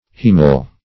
hiemal - definition of hiemal - synonyms, pronunciation, spelling from Free Dictionary